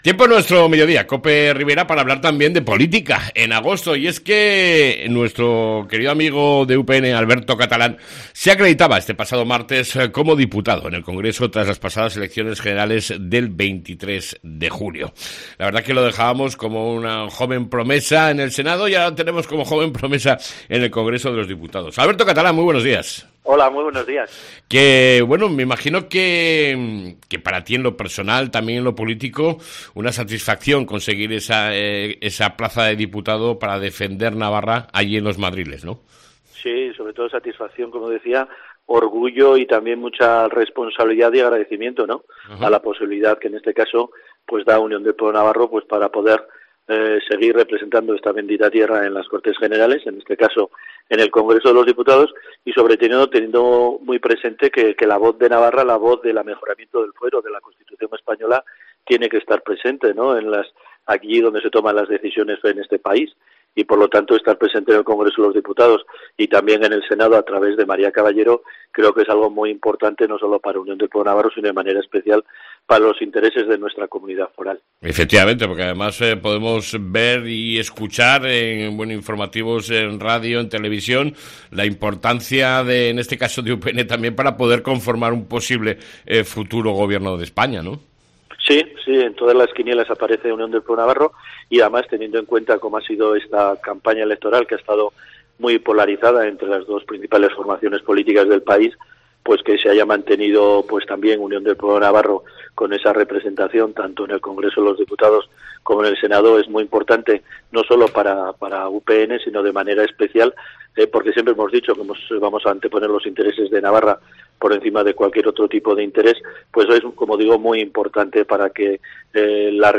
ENTREVISTA CON EL DIPUTADO DE UPN , ALBERTO CATALÁN